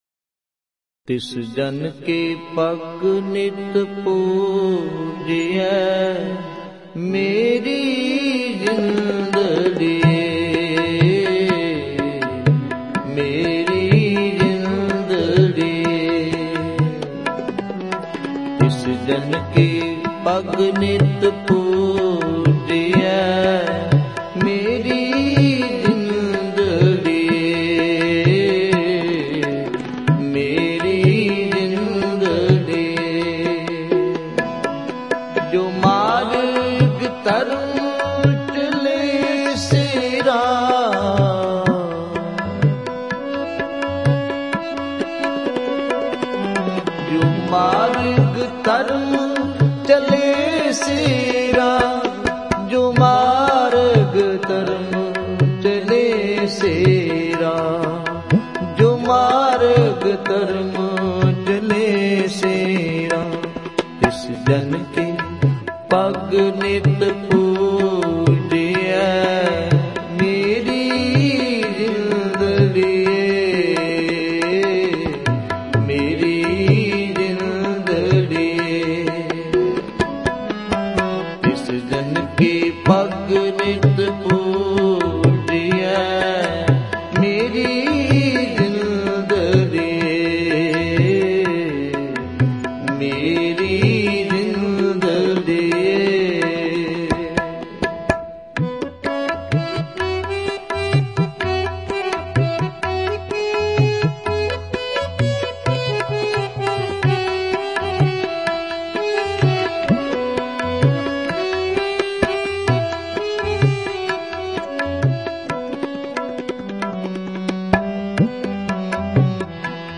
Hazoori Ragi Sri Darbar Sahib Amritsar
Genre: Shabad Gurbani Kirtan